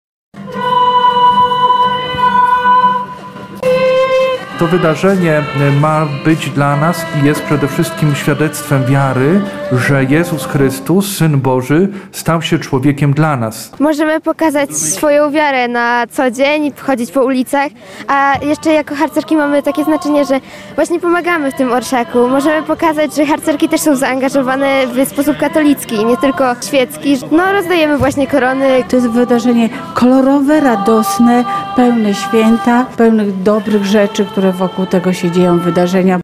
– Możemy pokazać swoją wiarę, chodzić po ulicach – mówią uczestnicy.
CZYTAJ: Orszak Trzech Króli przeszedł ulicami Lublina [ZDJĘCIA, FILM] Uczestnikom towarzyszył śpiew kolęd i przedstawienie jasełek ulicznych.